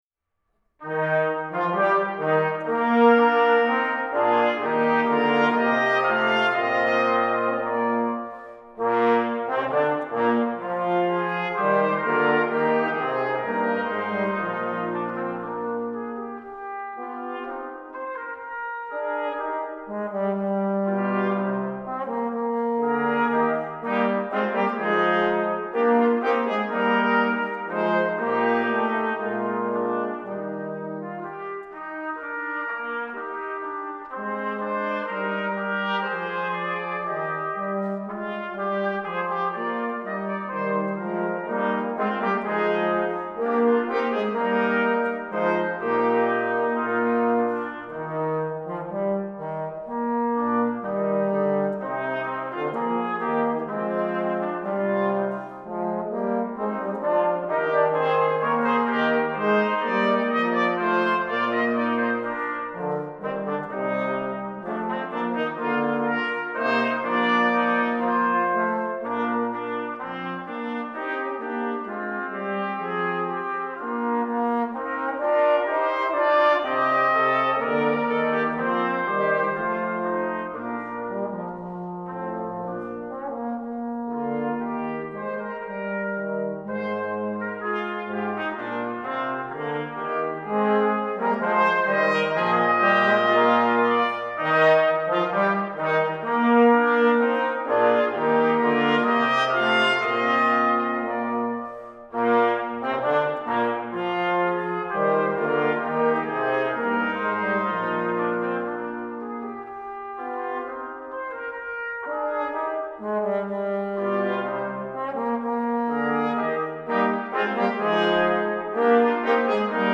Warm, weit, strahlend – muss man selbst hören
Die Aufnahmen wurden von verschiedenen Ensembles aufgenommen und zur Verfügung gestellt.
Christian Schaefer, Solistische Besetzung der Badischen Posaunenarbeit, Gloria 2024 S. 16